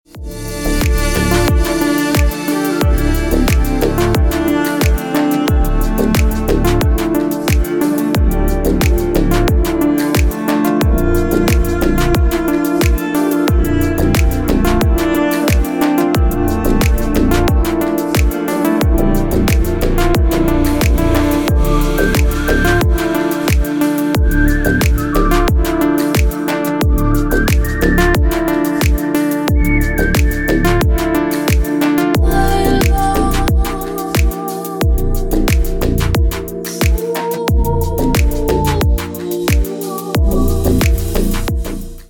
Красивые мелодии на телефон, мелодичные рингтоны Качество